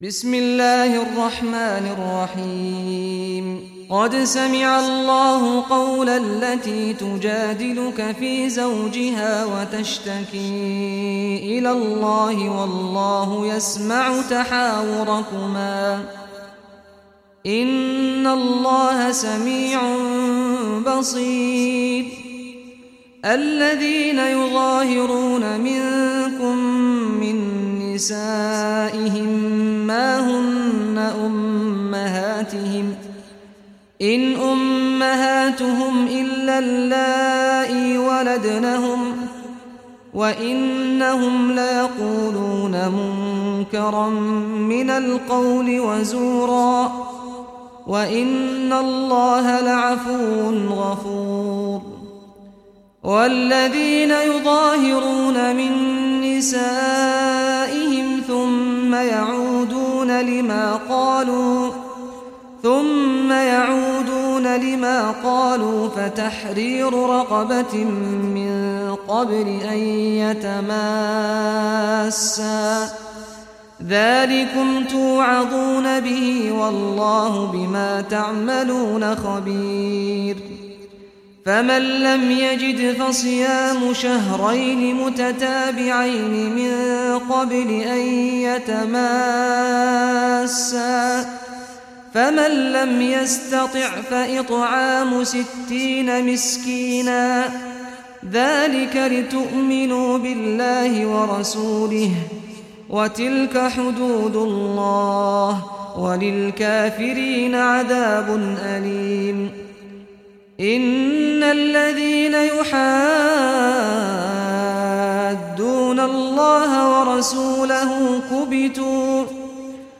Surah Al-Mujadila Recitation by Sheikh Saad Ghamdi
Surah Al-Mujadila, listen or play online mp3 tilawat / recitation in Arabic in the beautiful voice of Sheikh Saad al Ghamdi.